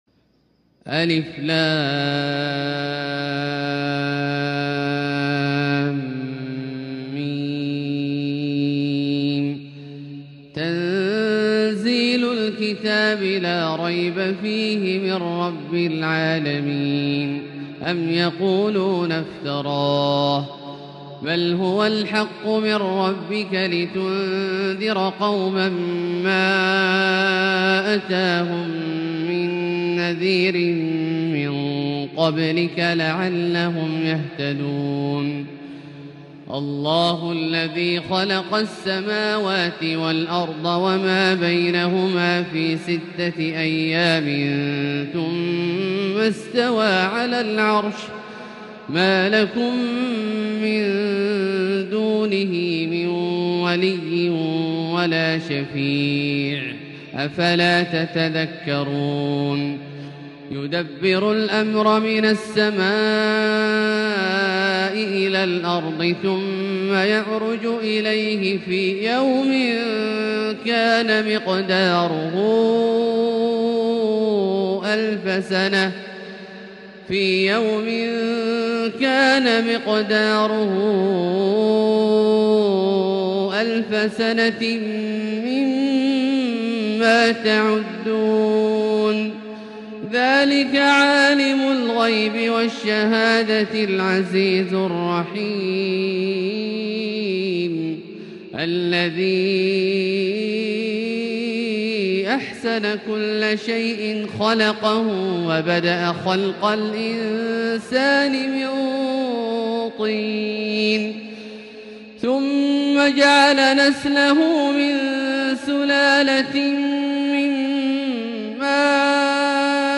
تلاوة ممتعة رائعة لـ سورة السجدة كاملة للشيخ د. عبدالله الجهني من المسجد الحرام | Surat As-Sajdah > تصوير مرئي للسور الكاملة من المسجد الحرام 🕋 > المزيد - تلاوات عبدالله الجهني